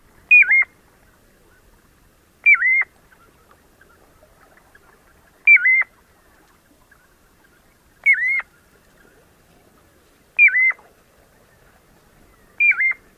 笛鸻鸟叫声